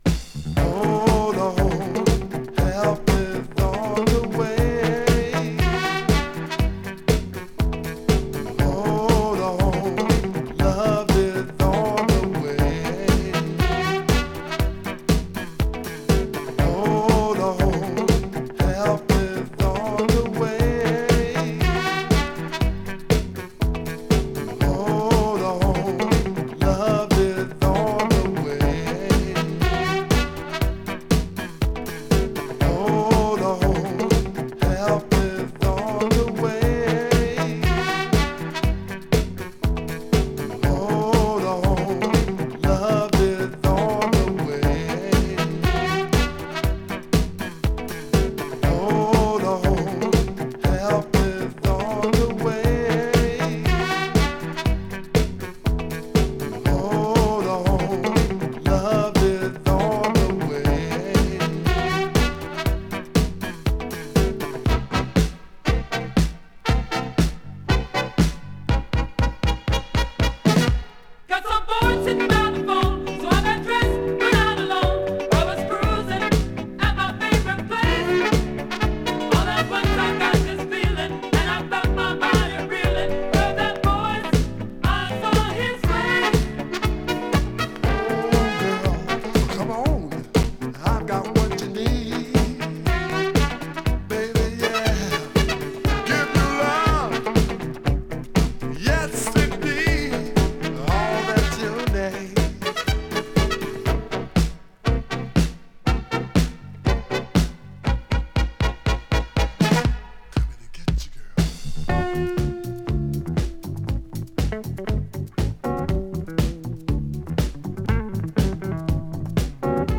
Loft & Garage Classic！
【CANADA】【DISCO】【BOOGIE】